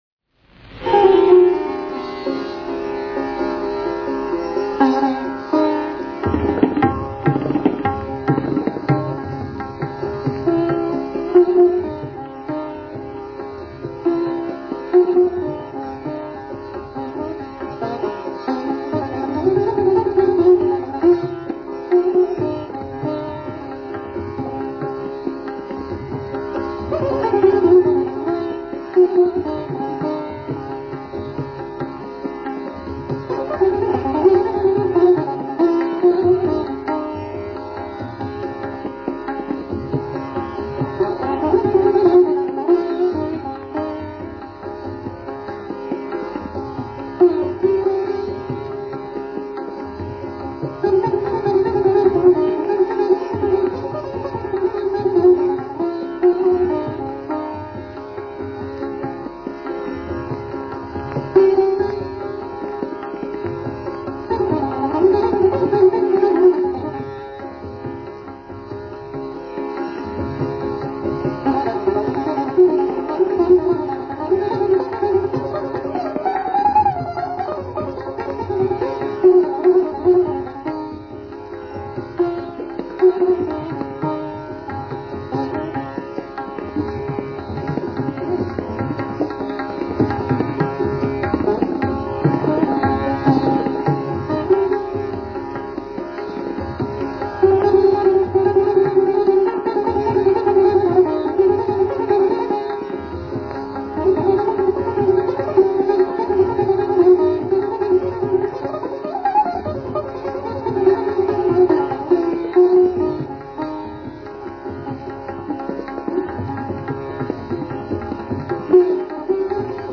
Tabla Sitar Gat